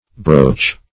broche - definition of broche - synonyms, pronunciation, spelling from Free Dictionary